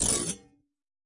描述：金属效果使用台虎钳固定锯片和一些工具来击打，弯曲，操纵。 所有文件都是96khz 24bit，立体声。
Tag: 研磨 尖叫 金属 耐擦 效果 声音